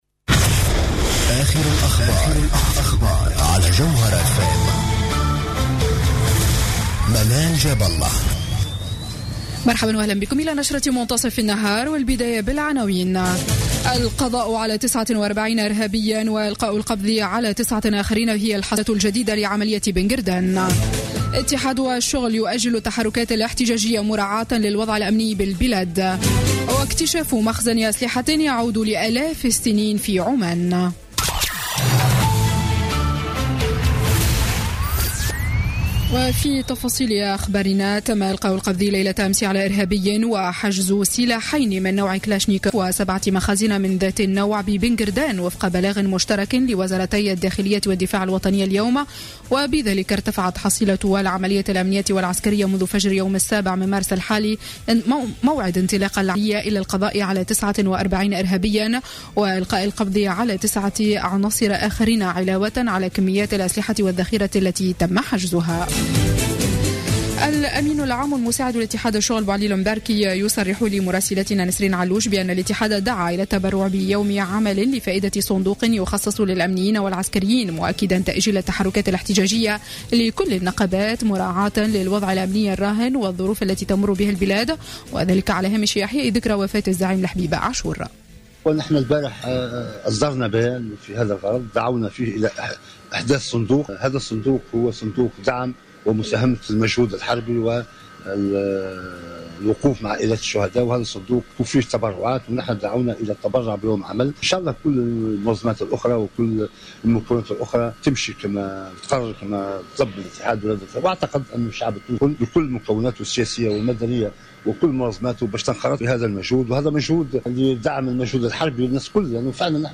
نشرة أخبار منتصف النهار ليوم السبت 12 مارس 2016